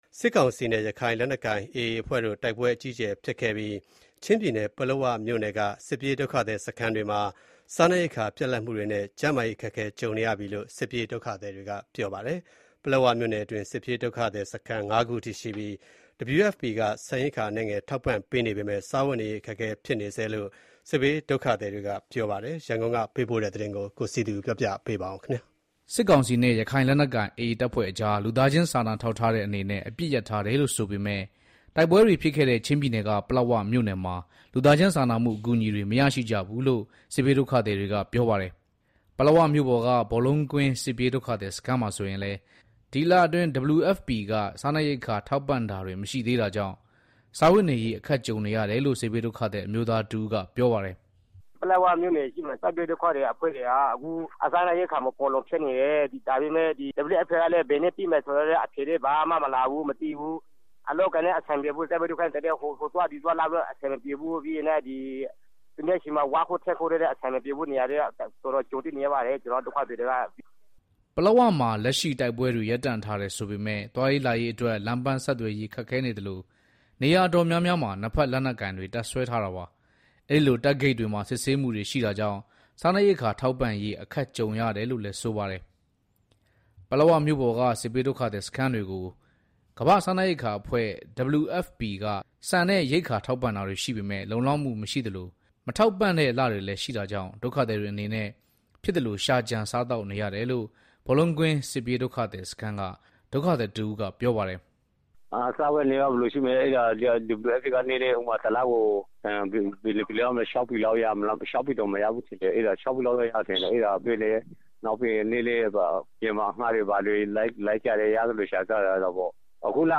ပလက်ဝမြို့ပေါ်က ဘောလုံးကွင်းစစ်ပြေးဒုက္ခသည်စခန်းမှာဆိုရင်လည်း ဒီလအတွင်း WFPက စားနပ်ရိက္ခာထောက်ပံ့တာတွေမရှိသေးတာကြောင့် စားဝတ်နေရေးအခက်ကြုံနေရတယ်လို့ စစ်ဘေးဒုက္ခသည် အမျိုးသားတဦးကပြောပါတယ်။